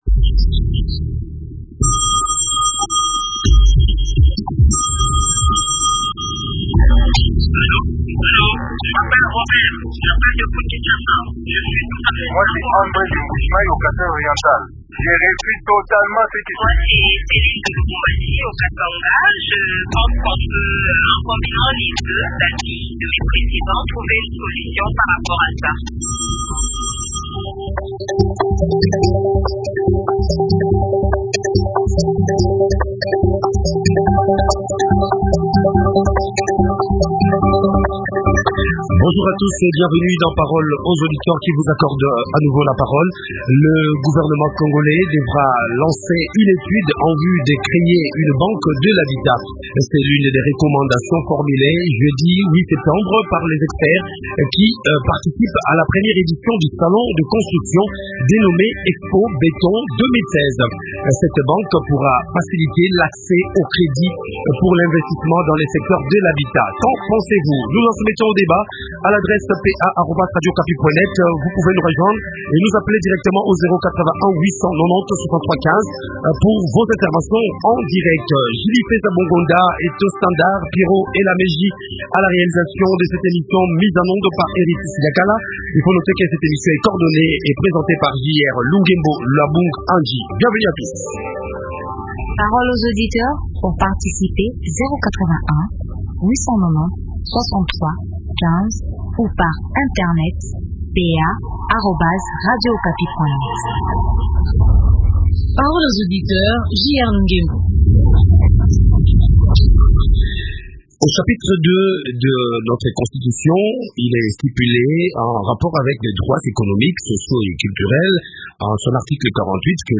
Invité : Jean Bamanisa Saïdi, promoteur de l’expobeton 2016.